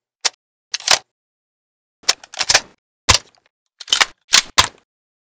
l85_reload_empty.ogg